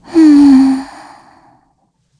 Gremory-Vox_Sigh_kr.wav